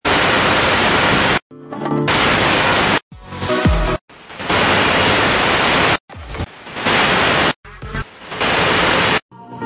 Radio Station